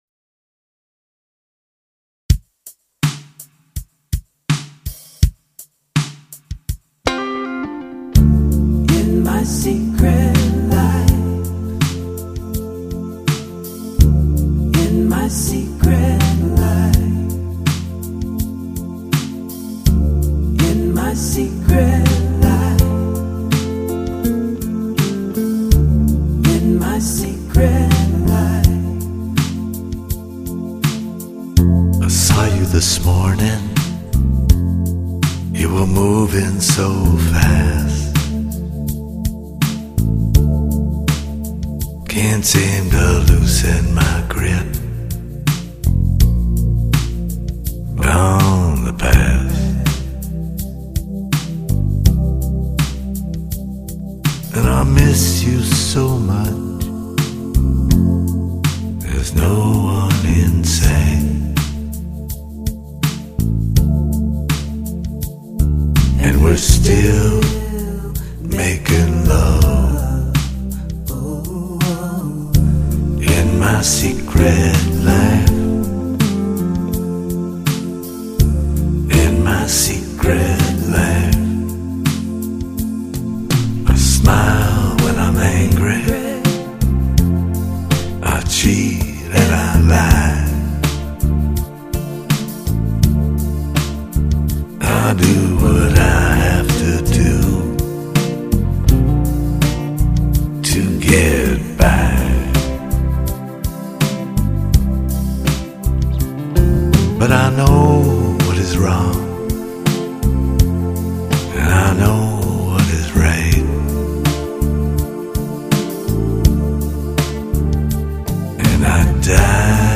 Genre: Folk Rock, Pop Rock